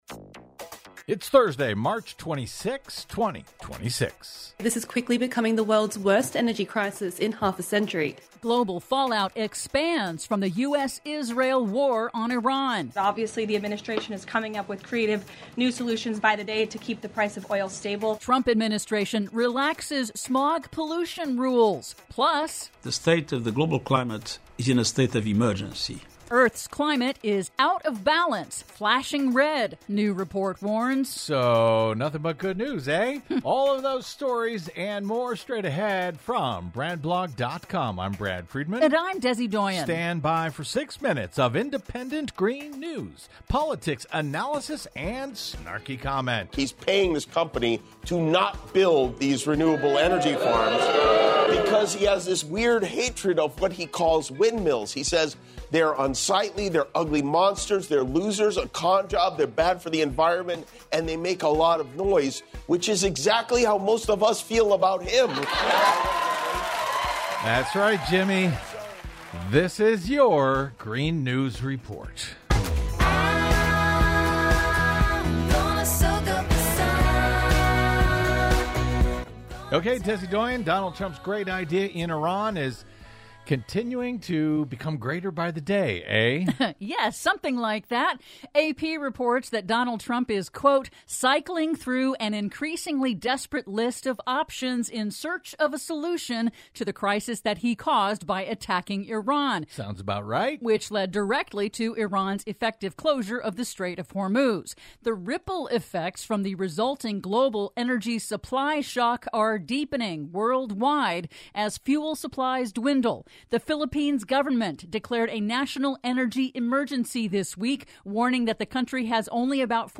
GNR's now celebrating 17 YEARS of independent green news, politics, analysis, snarky comment and connecting climate change dots over your public airwaves!Click here to help us celebrate with a donation!...